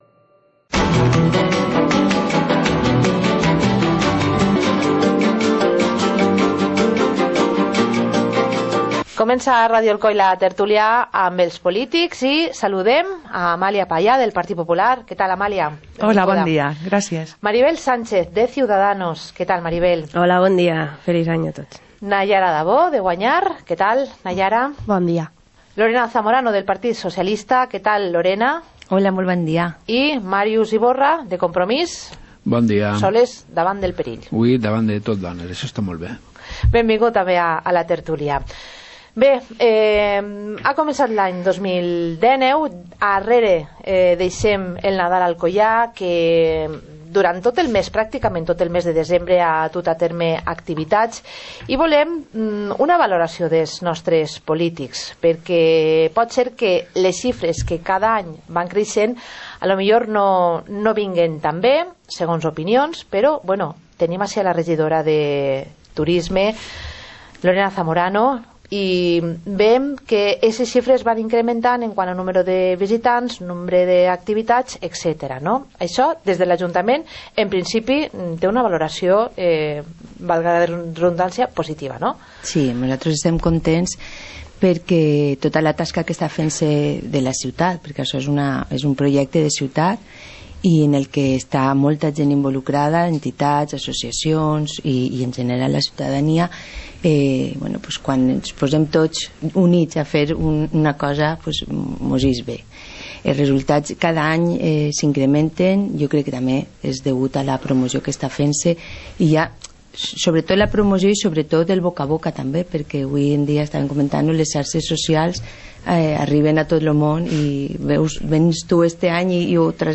Informativo comarcal - miércoles, 09 de enero de 2019